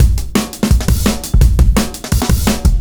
cw_170_BreakingUp1.wav